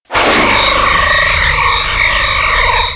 heavy tractor beam.ogg